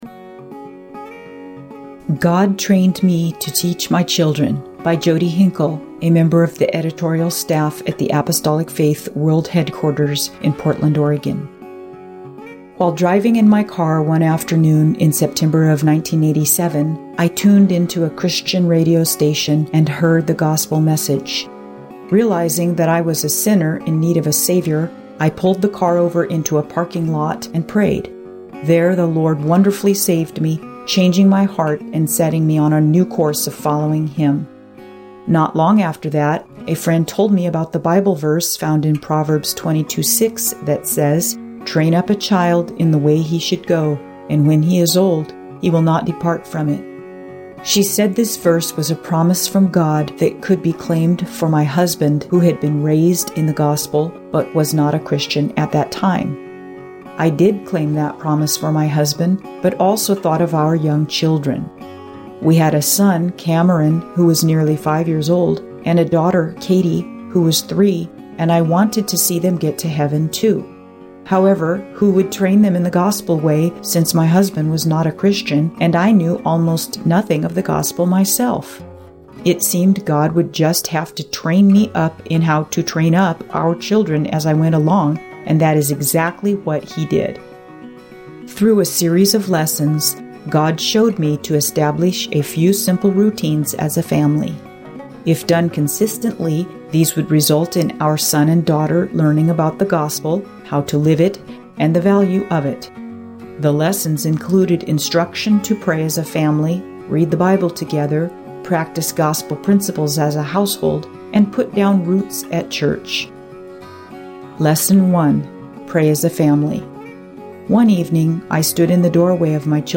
Witness | God taught this mother four simple family routines for raising her son and daughter in the Gospel.